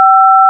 Les sons que vous allez traiter sont des signaux de codes DTMF (Dual Tone Multiple Frequency) utilisés pour distinguer par le son les touches numérotées d'un clavier téléphonique (DTMF sur wikipedia).
L'appuie sur une touche génère un son composé de deux fréquences, selon le tableau suivant :